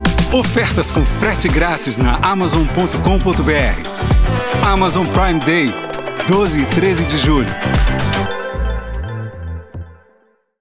Promotions
Ma voix est naturelle et professionnelle. Elle est souvent décrite comme crédible, veloutée et douce, inspirant confiance et calme à l'auditeur.
Microphone : Neumann TLM103
Cabine vocale acoustiquement isolée et traitée
BarytonBasseProfondBas